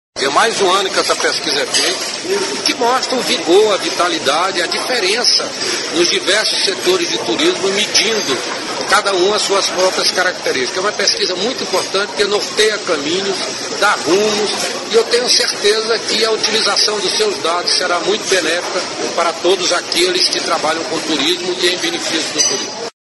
aqui para ouvir declaração do ministro do Turismo, Gastão Vieira, sobre a importância do Índice de Competitividade do Turismo Nacional.